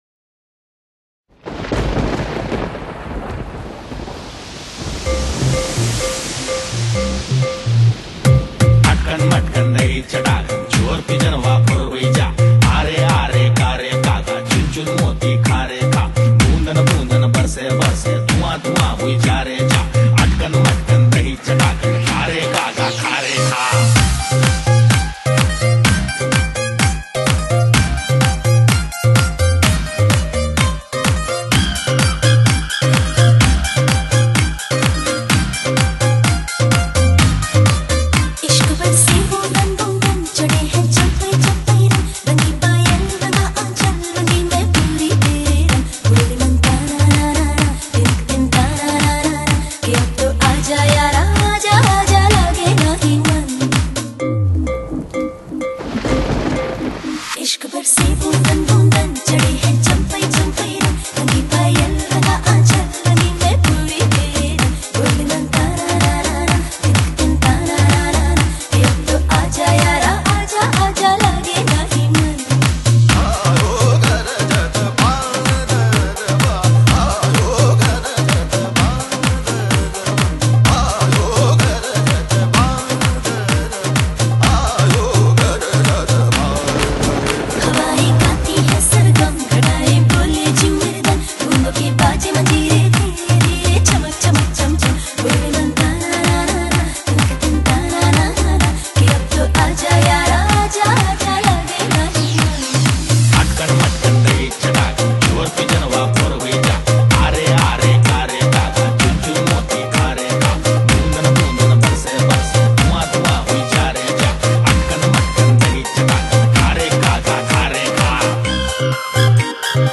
Genre: Dance